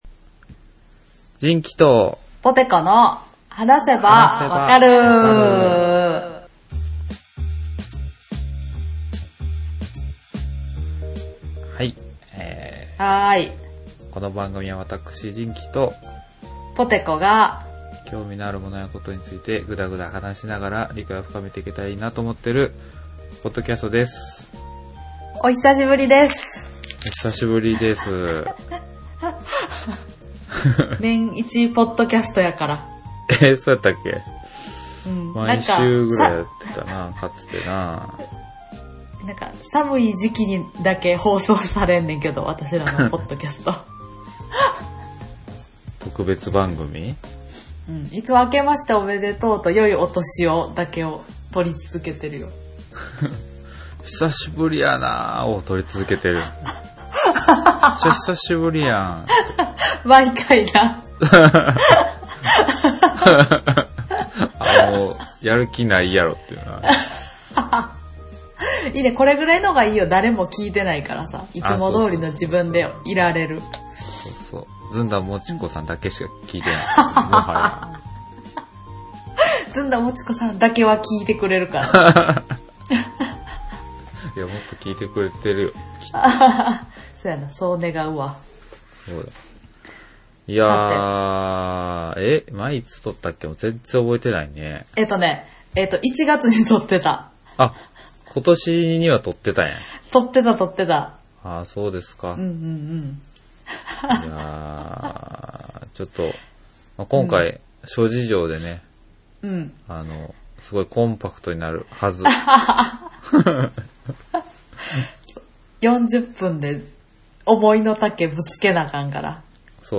飽き性で眠そうに話す男